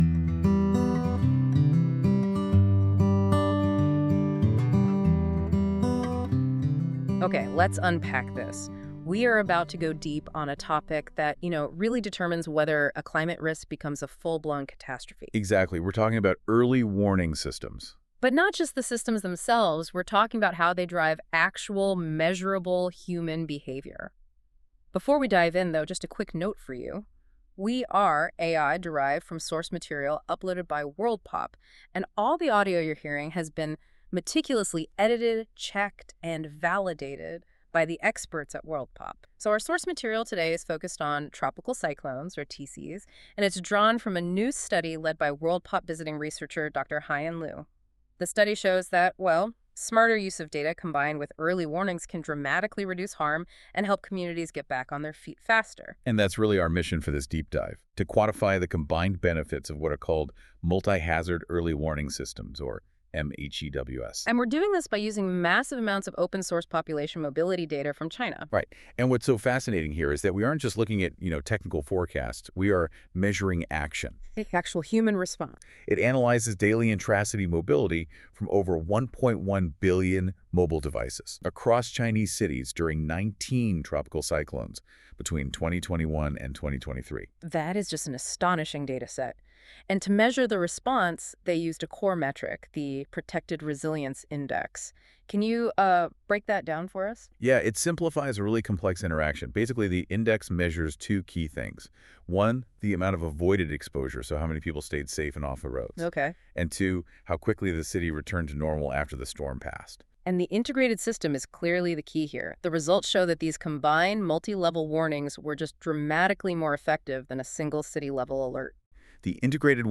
This feature uses AI to create a podcast-like audio conversation between two AI-derived hosts that summarise key points of documents - in this case the “Combined benefits of multi-hazard early warnings on human mobility resilience to tropical cyclones” journal article linked below.